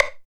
62 HI STIK-R.wav